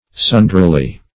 sundrily - definition of sundrily - synonyms, pronunciation, spelling from Free Dictionary Search Result for " sundrily" : The Collaborative International Dictionary of English v.0.48: Sundrily \Sun"dri*ly\, adv.